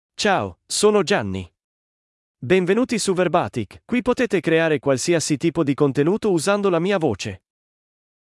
MaleItalian (Italy)
GianniMale Italian AI voice
Gianni is a male AI voice for Italian (Italy).
Voice sample
Gianni delivers clear pronunciation with authentic Italy Italian intonation, making your content sound professionally produced.